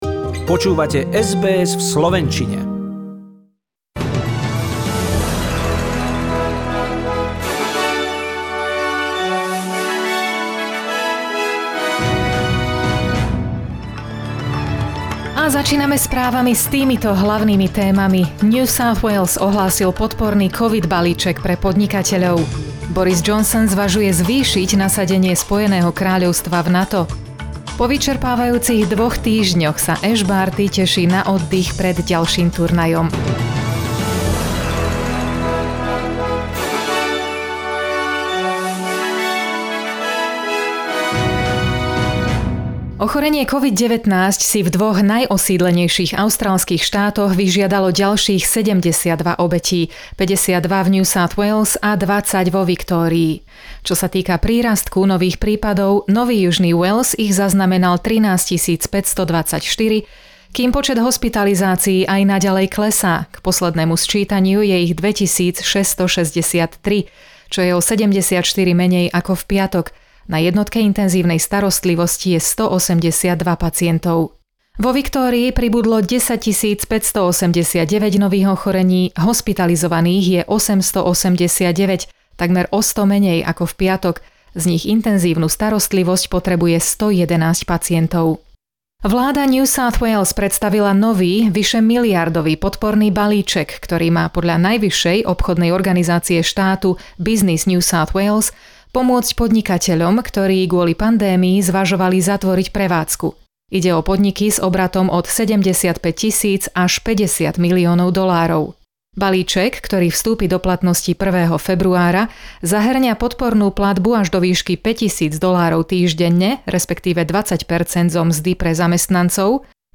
SBS NEWS - správy v slovenčine